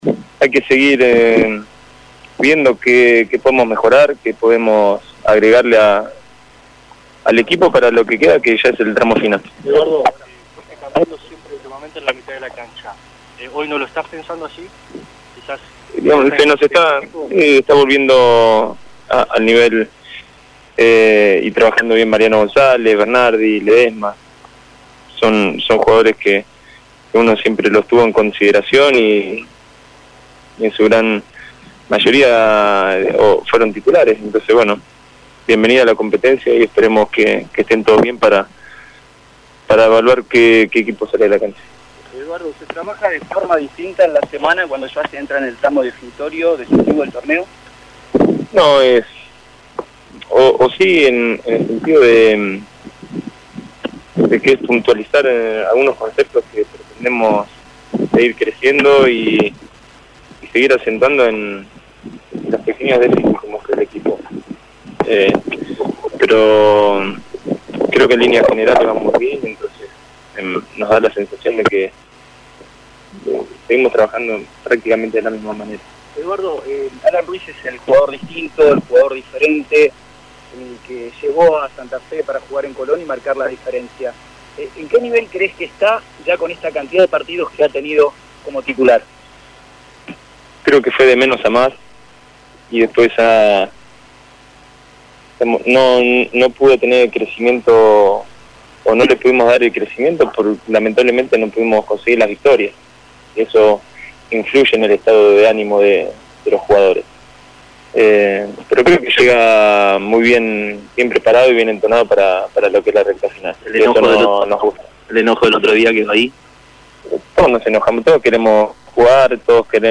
El entrenador sabalero habló con la prensa luego de la práctica que mantuvo su equipo esta mañana. Colón se prepara para recibir a Patronato el próximo lunes a las 19 en el Brigadier Estanislao López.